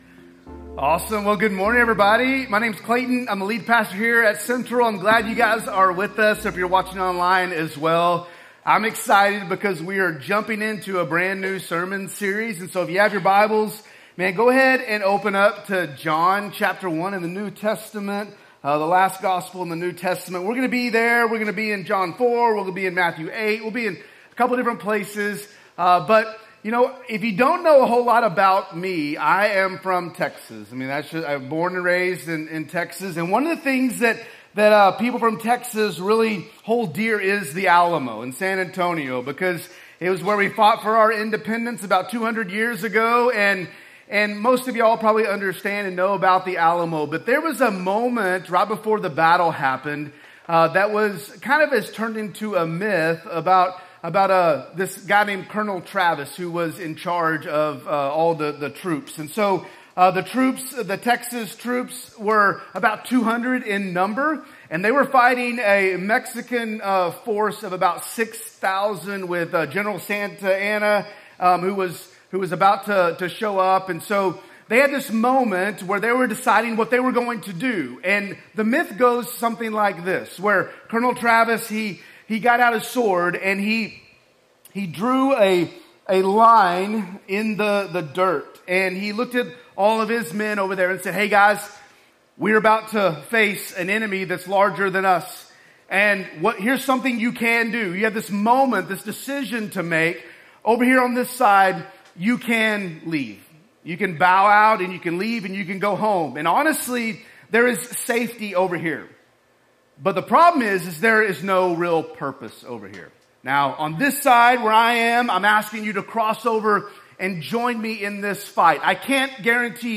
A message from the series "Crossing the Line."